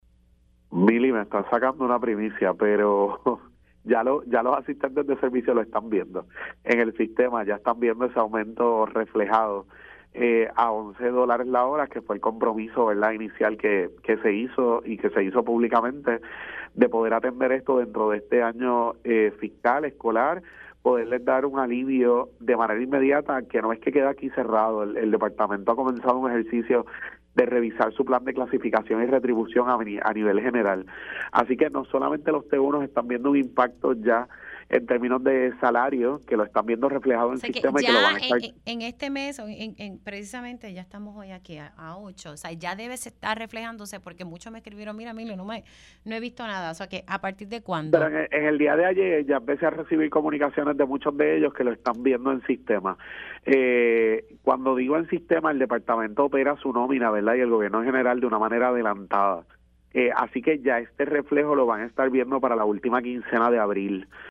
El secretario de Educación, Eliezer Ramos confirmó en Pega’os en la Mañana que ya se debe ver reflejado el aumento a los asistentes T-1 del Programa de Educación Especial en sus nóminas.